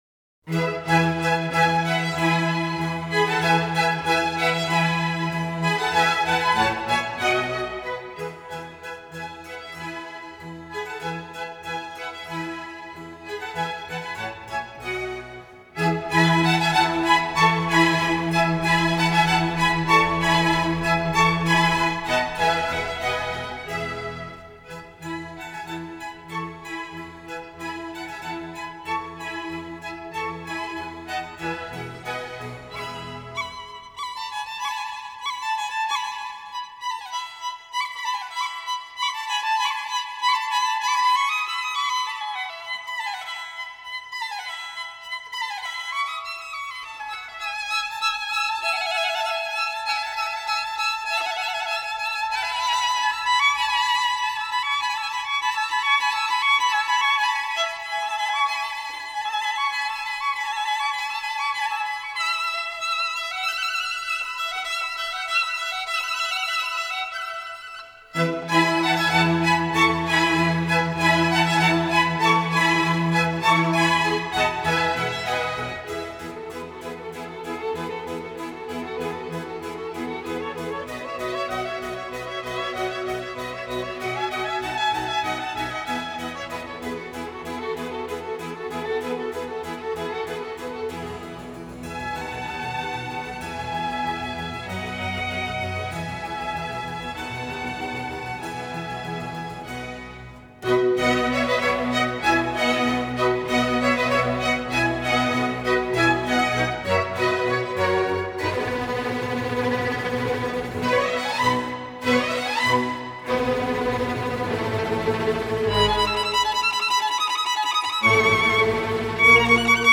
专辑类型：轻古典
振奋人心的轻古典《推荐您赖床时聆赏》